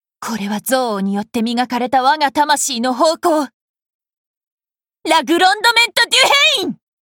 jeanne_darc_alter_voice_noble_phantasm_6vwenWN.mp3